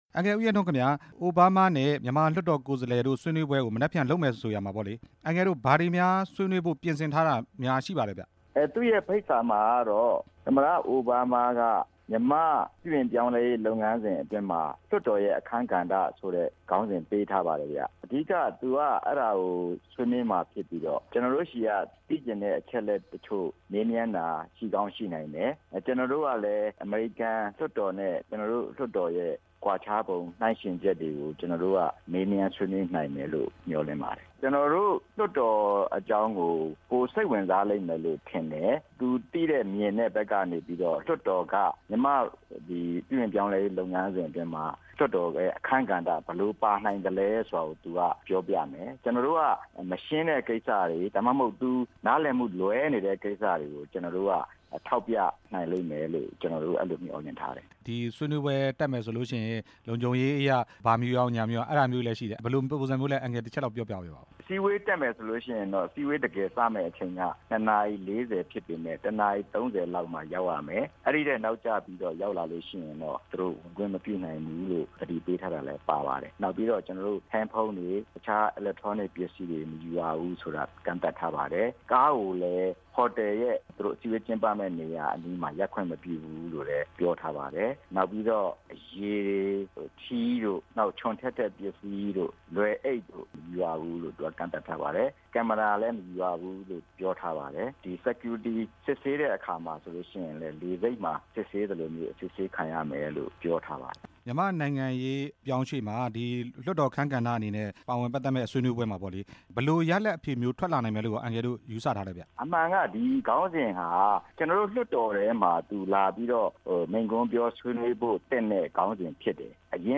ပြည်သူ့လွှတ်တော် ကိုယ်စားလှယ် ဦးရဲထွန်းနဲ့ မေးမြန်ချက်